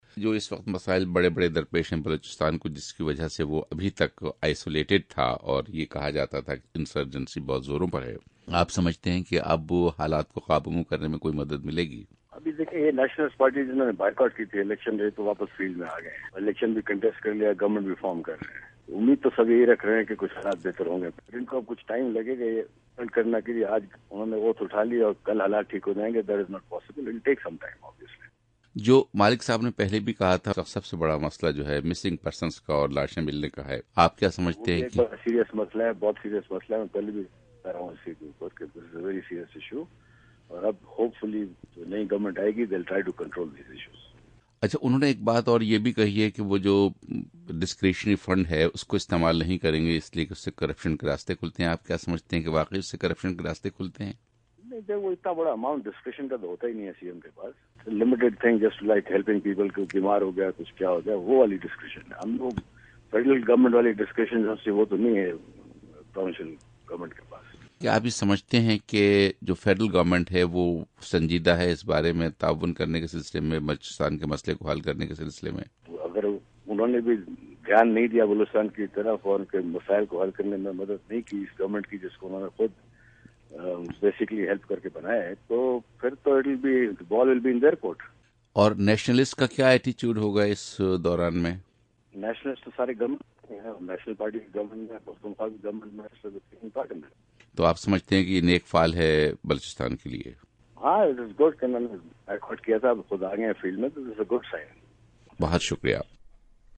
گورنر بلوچستان ذوالفقار مگسی سے انٹرویو